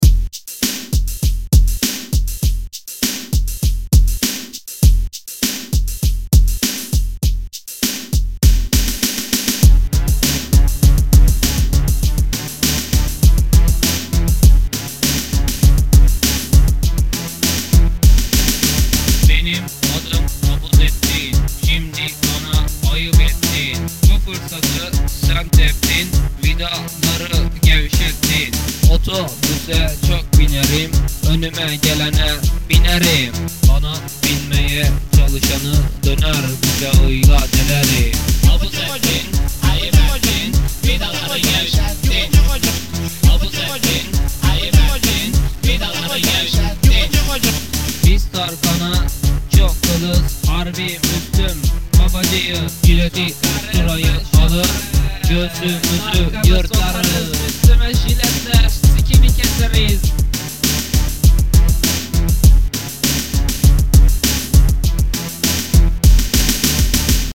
Bu arada şarkılara kötü diye falan tepkiler geliyor bu şarkının 98 yılında yapıldığını belirtmek isterim stüdyo falan da yoktu yani ;) Ayrıca bu şarkıyı kaydederken güldüğüm kadar hayatımda çok az gülmüşümdür